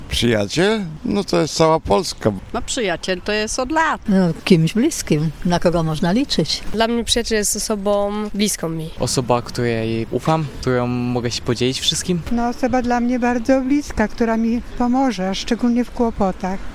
Mieszkańcy Stargardu podzielili się z nami, kim dla nich jest prawdziwy przyjaciel.